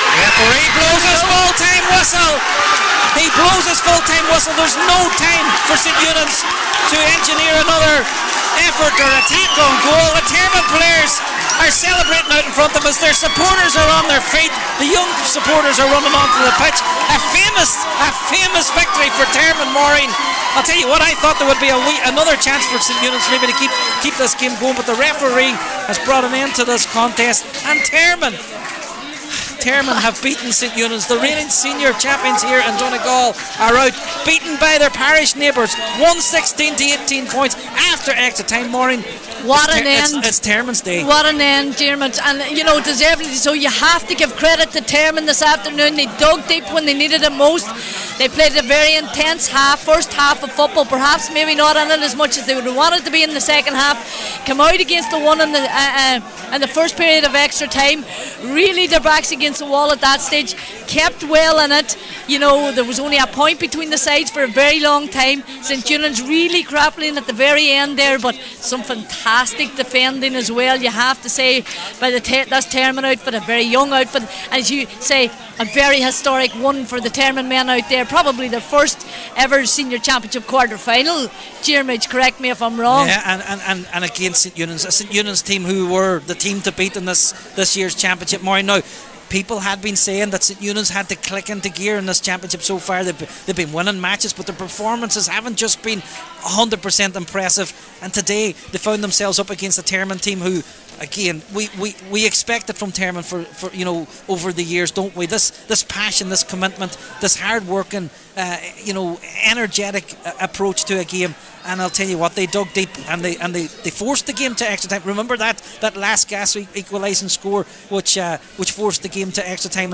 were live at full time…